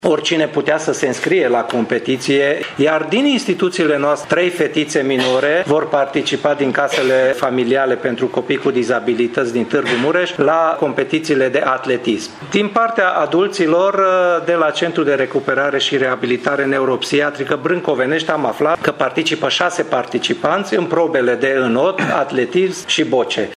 Programul manifestării a fost anunțat, astăzi, în cadrul unei conferințe de presă.